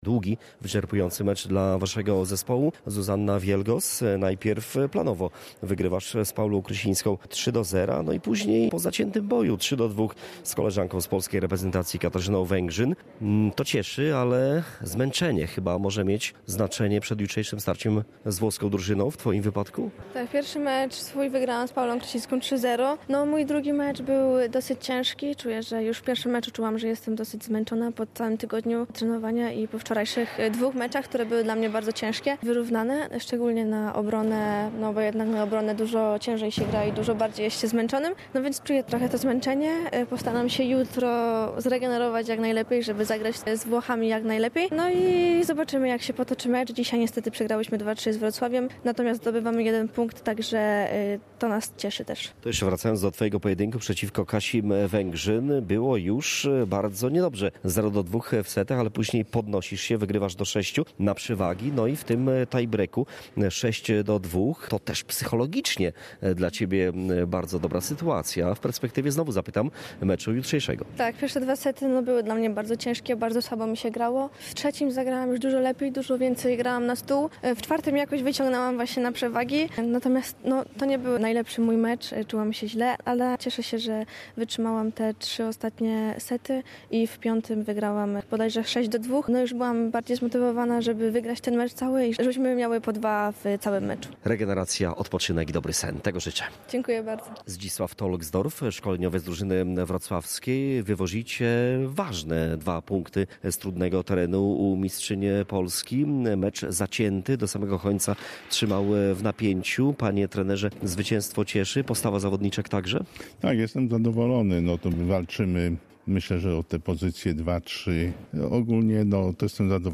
Pomeczowe komentarze